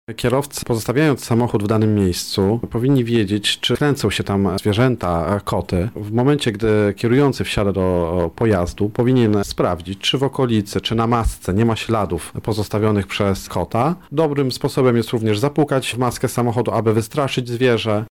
młodszy aspirant